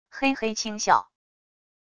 嘿嘿轻笑wav音频